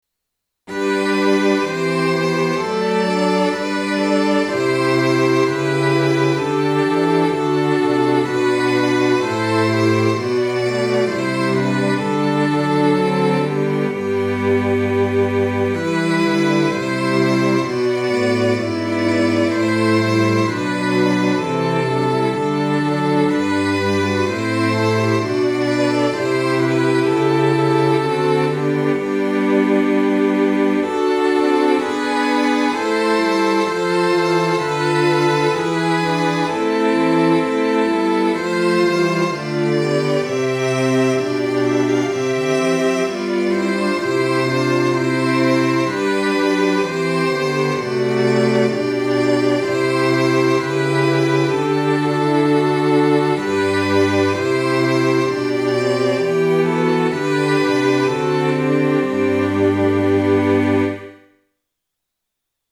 Sample Sound for Practice 練習用参考音源：MIDI⇒MP3　Version A.17
Tonality：G (♯)　Tempo：Quarter note = 64
1　 Strings